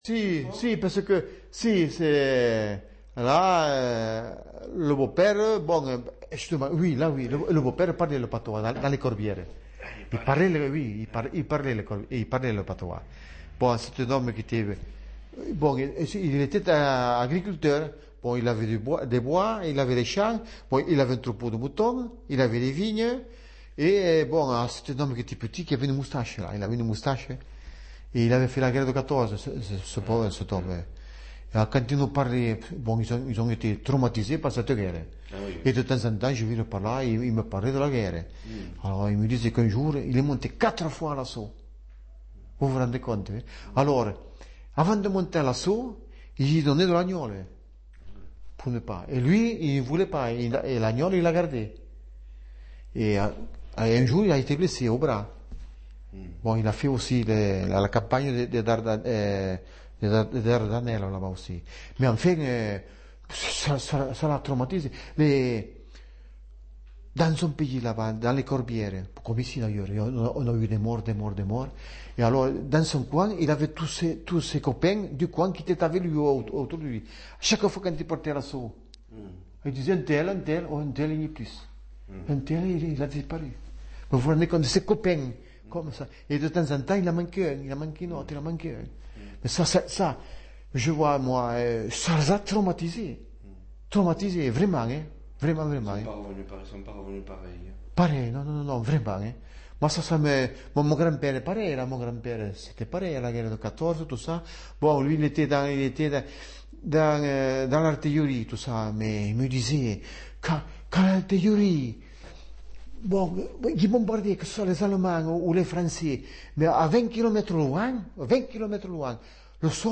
Les variétés du français parlé dans l'espace francophone. Ressources pour l'enseignement
Partie III La France hexagonale méridionale
Chapitre 2. Conversation à Douzens (Aude) : souvenirs des deux guerres mondiales (7’54) (Durand & Rossi-Gensane)